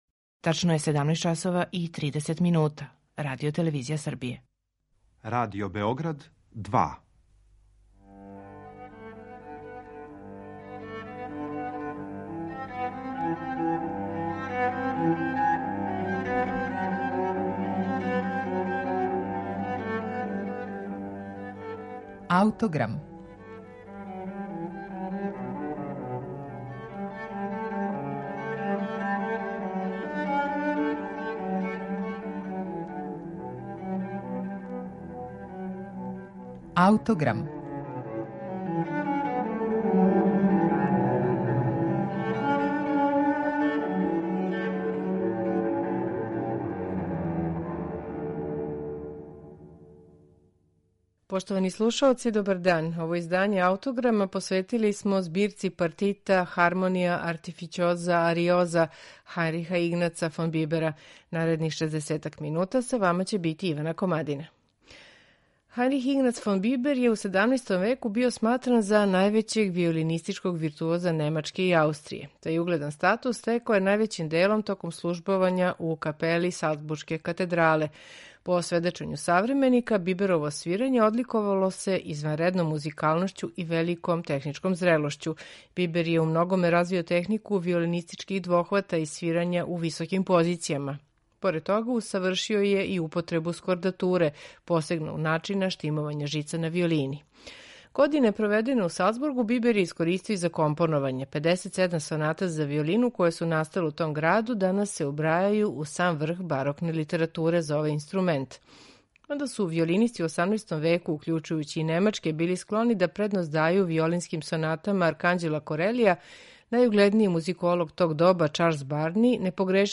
на оригиналним инструментима Биберовог доба
виолина и виола д'аморе
виолине
виола да гамба
на оргуљама